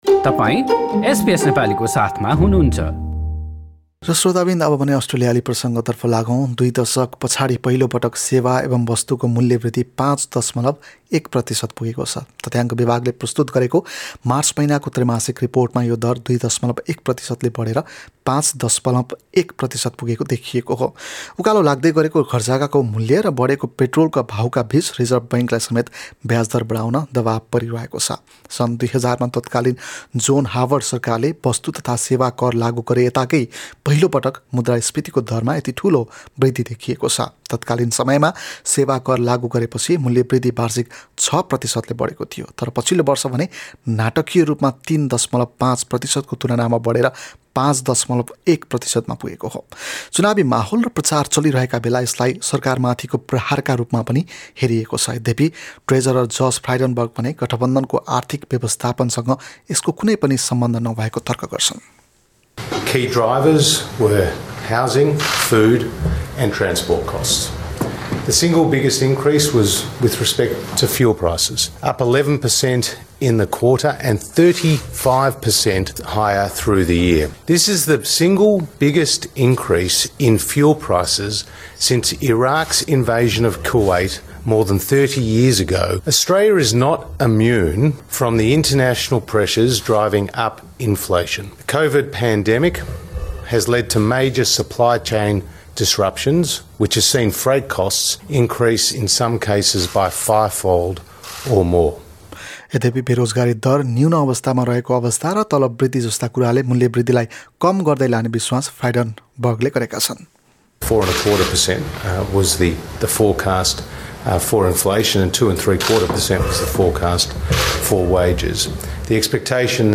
ट्रेजरर फ्राइडनबर्गको भनाइ सहितको रिपोर्ट यहाँ सुन्नुहोस्: ब्याजदर बढाउने दबाबमा रिजर्भ बैङ्क हाम्रा थप अडियो प्रस्तुतिहरू पोडकास्टका रूपमा उपलब्ध छन्।